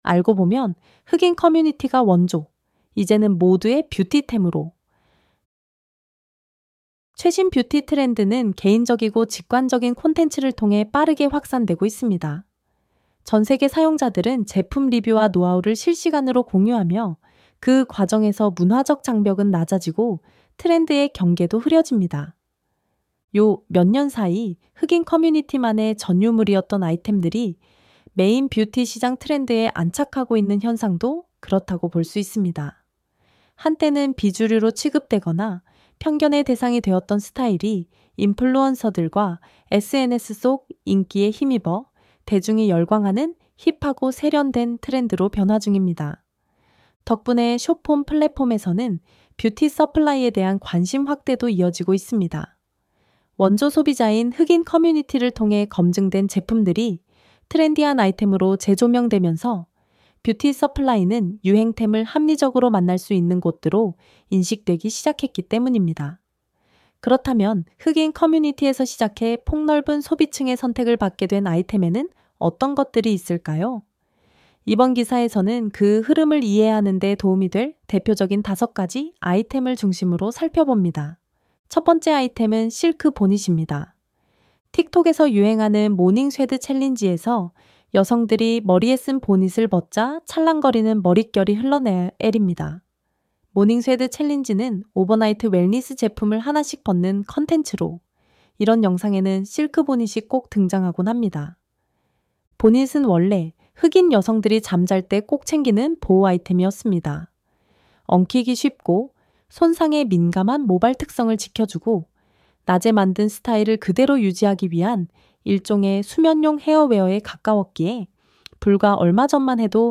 ElevenLabs_TREND_흑인원조_뷰티템.mp3